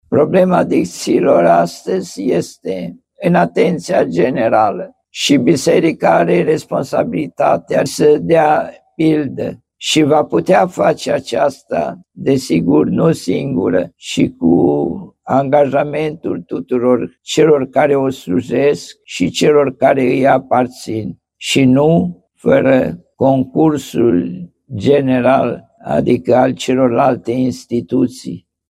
După sfinţirea locului, arhiepiscopul Aradului, ÎPS Timotei Seviciu, a spus că Biserica are responsabilitatea de a avea grijă “de cei oropsiţi ai soartei, într-o dependenţă parţială sau totală de alţii”.